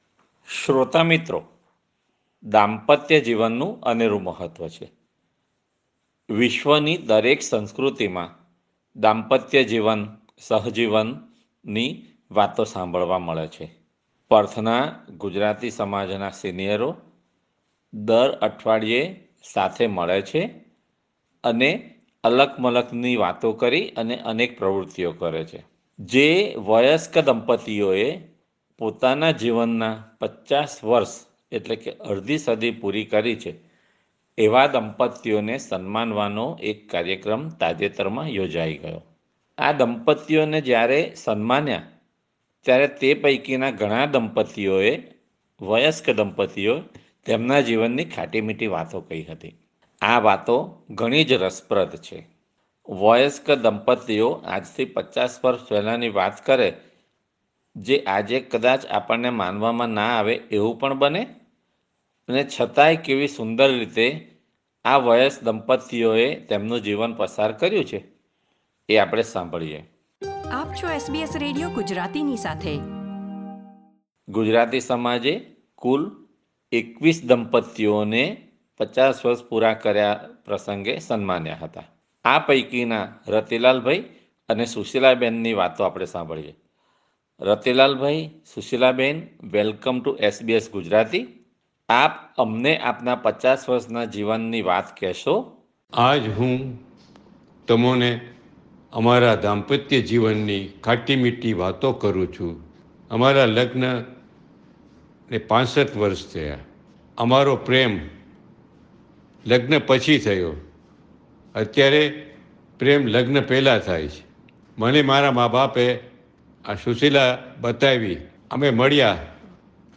દાંપત્યજીવનમાં 50 વર્ષ પૂર્ણ કરનારા દંપત્તિઓને તાજેતરમાં પર્થ ખાતે યોજાયેલા એક કાર્યક્રમમાં સન્માન કરવામાં આવ્યું હતું. સન્માનિત થયેલા દંપત્તિઓએ તેમના જીવનની કેટલીક યાદગાર પળો SBS Gujarati સાથે વહેંચી હતી.